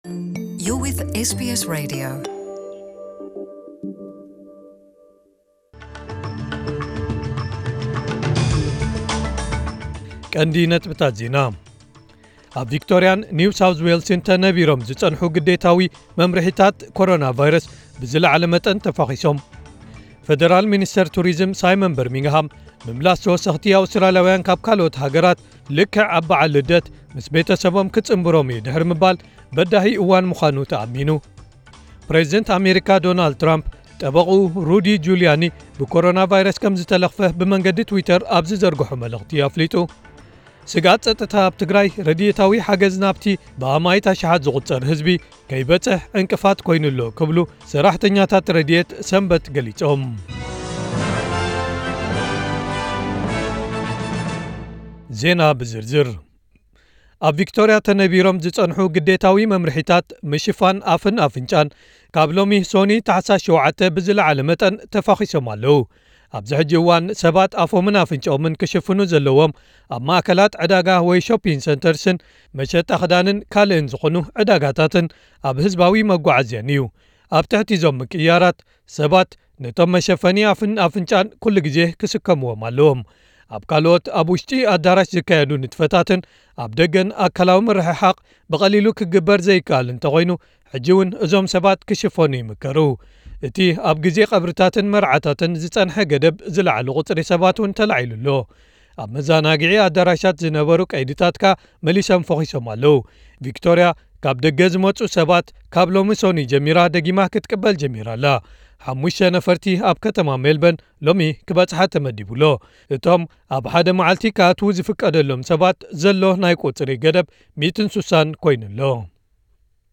ዕለታዊ ዜና